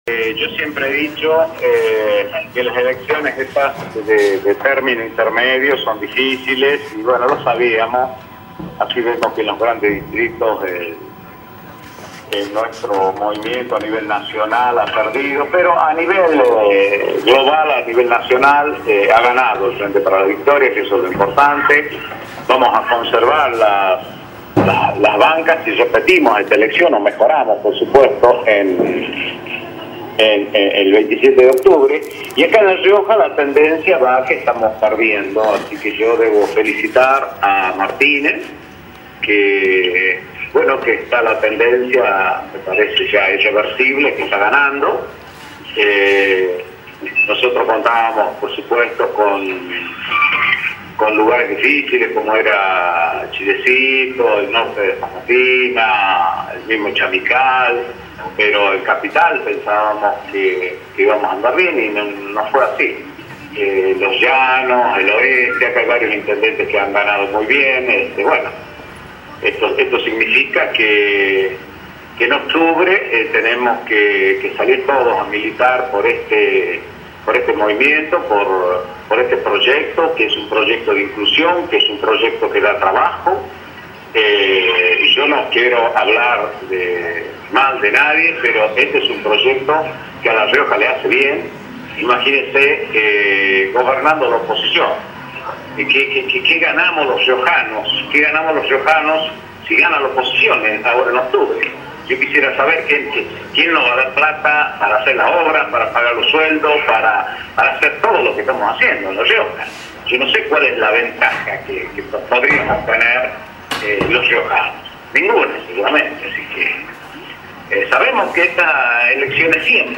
Conferencia de prensa del gobernador Luis Beder Herrera
aaa39-conferencia-de-prensa-del-gobernador-beder-herrera-9-min-52-seg.mp3